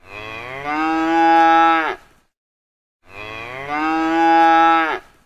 Sapi_Suara.ogg